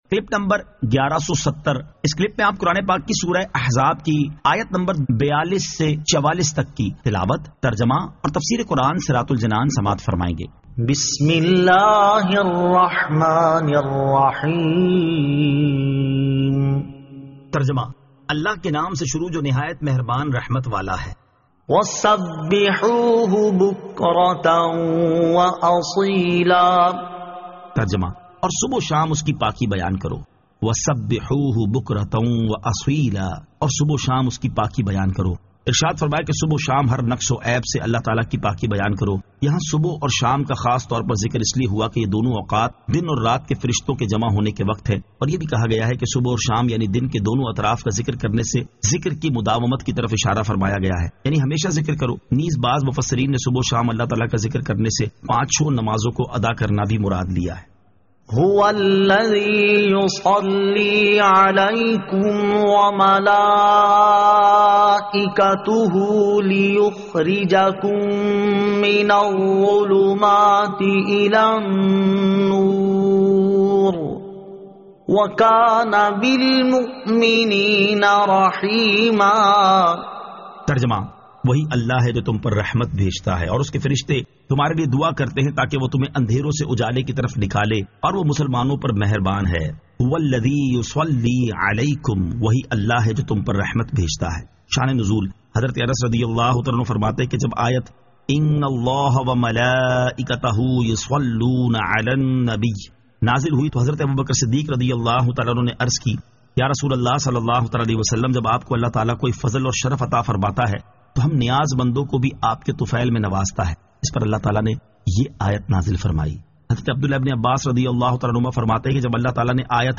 Surah Al-Ahzab 42 To 44 Tilawat , Tarjama , Tafseer